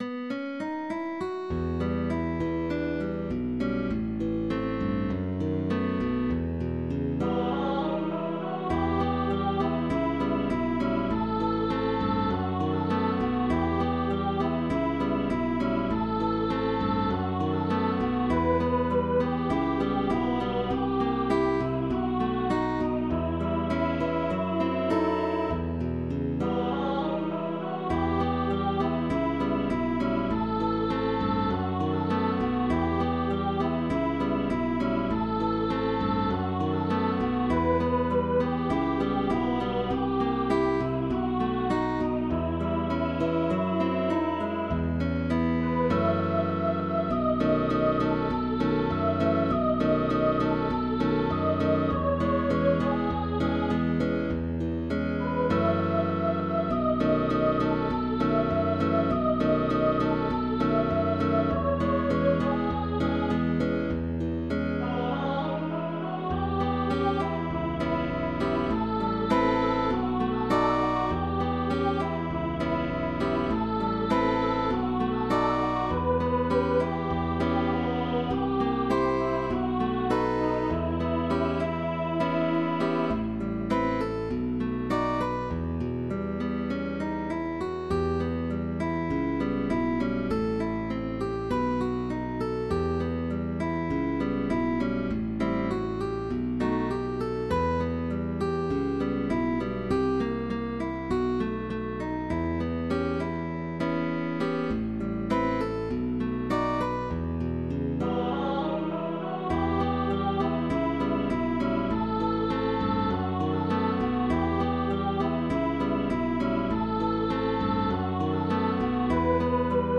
bolero
arreglado para voz y guitarra clásica con bajo opcional.